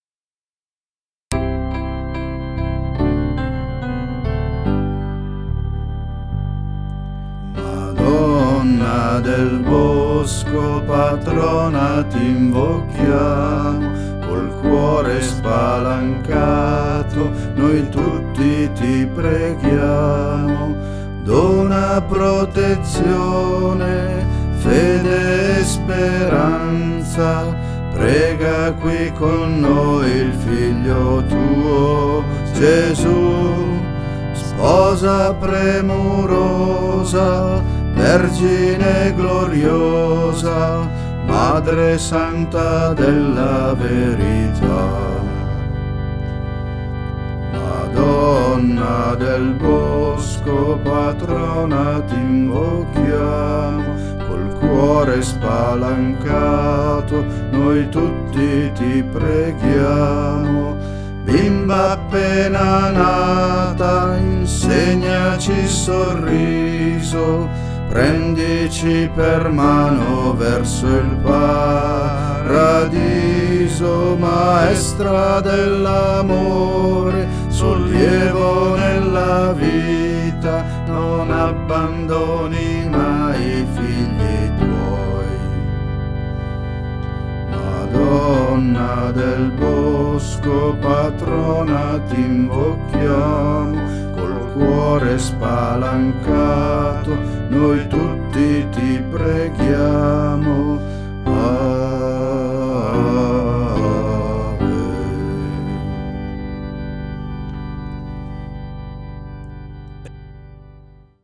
INNO ALLA MADONNA DEL BOSCO (canto composto per il centenario)